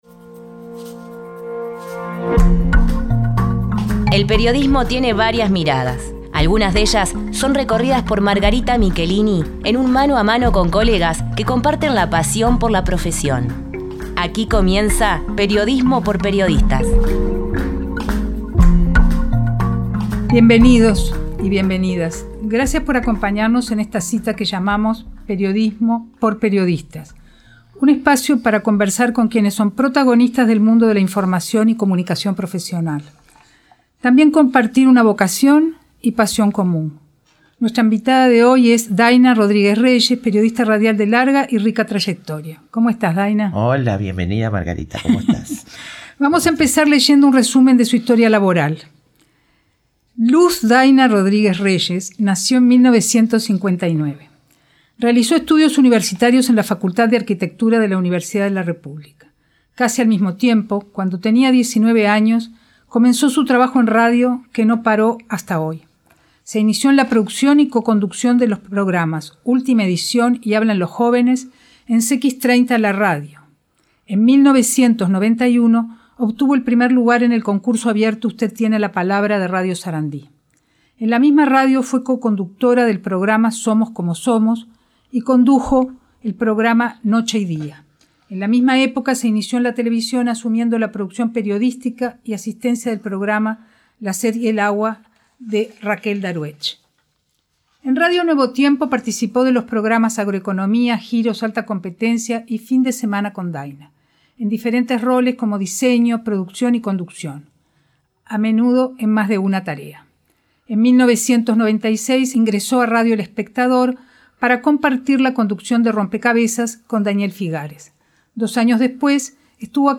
Esa búsqueda y reconstrucción de la disciplina, sólo es posible a través de un «mano a mano», con cinco colegas con los que a partir de sus experiencias y vivencias, va delineando diversos aspectos de la profesión en el contexto humano, social e histórico en el que se desarrolla.